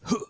Male Hit 1.wav